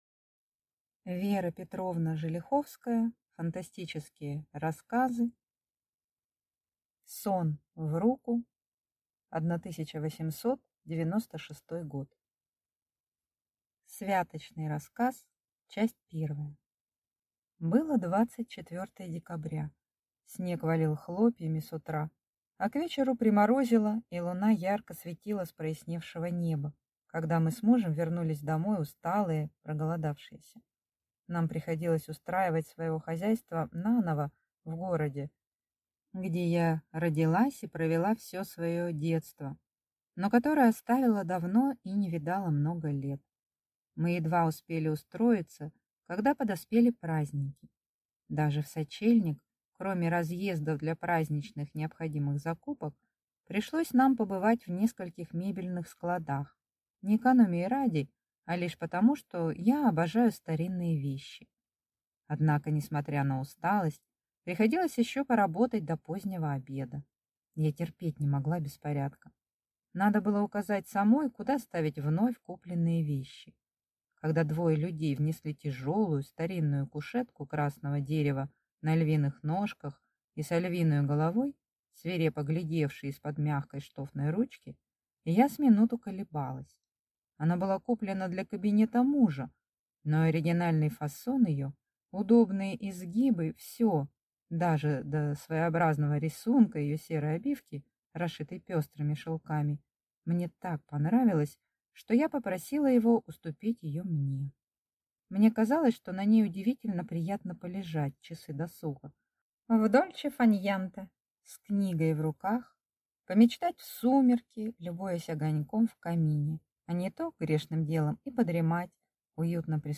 Аудиокнига Сон в руку | Библиотека аудиокниг